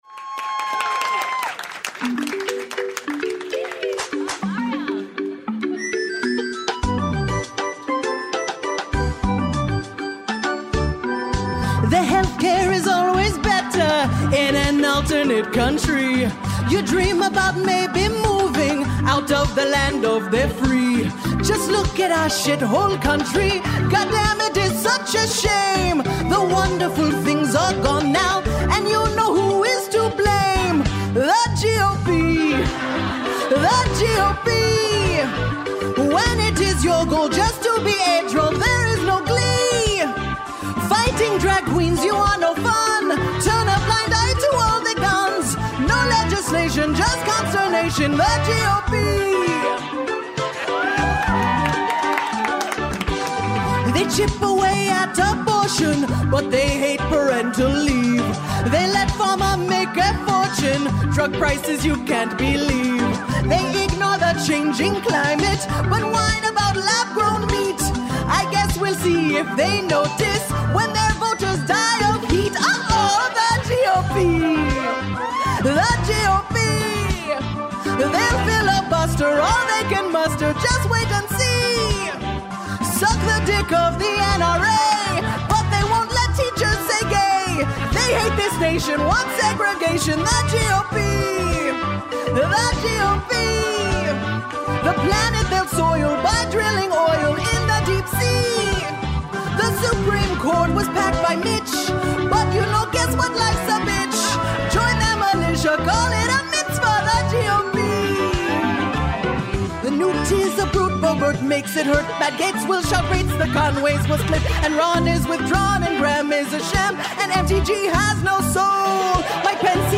Lovett Or Leave It welcomes all the beautiful people to Los Angeles’s gorgeous Dynasty Typewriter theater, including Keep It’s own Oscars trivia star Louis Virtel.